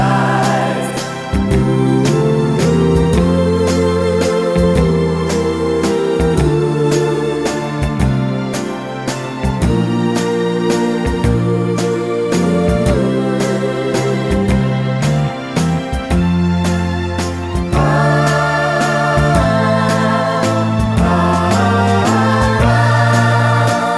No Backing Vocals Crooners 3:10 Buy £1.50